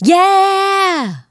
Yeah-G.wav